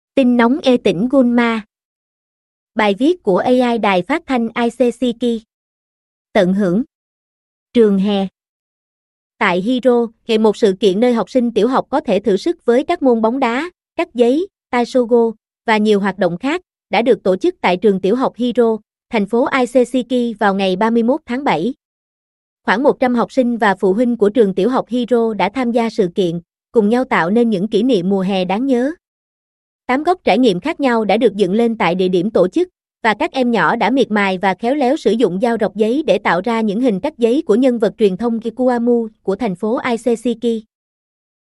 Tin nóng "Tỉnh Gunma".Bài viết của AI đài phát thanh Isesaki."Tận hưởng!
Audio Channels: 1 (mono)